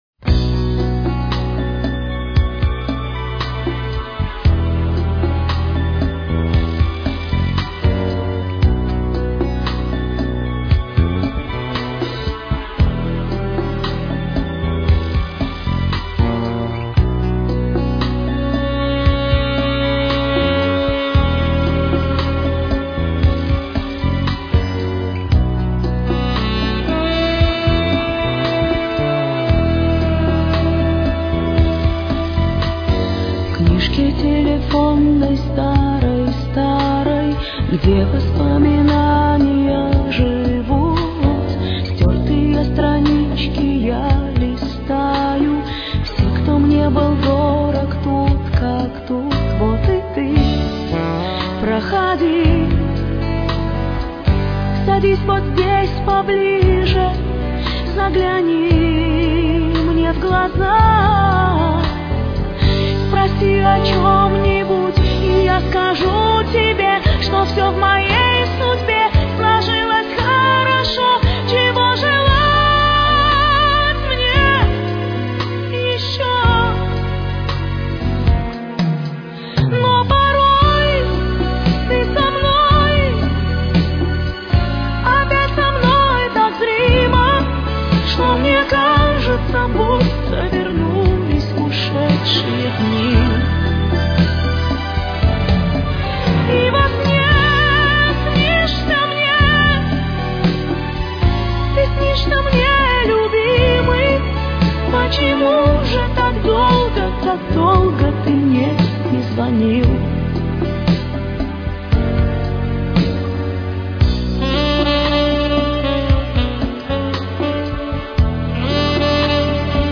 с очень низким качеством (16 – 32 кБит/с)
Си минор. Темп: 118.